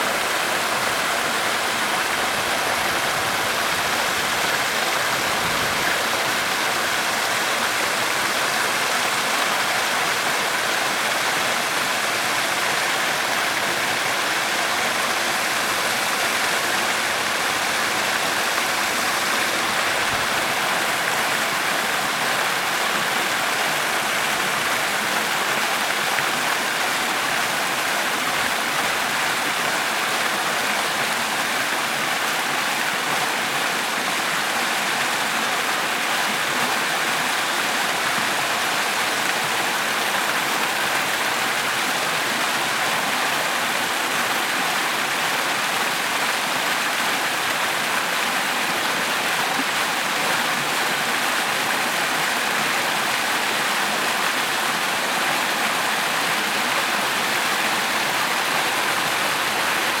【効果音】滝の音 - ポケットサウンド - フリー効果音素材・BGMダウンロード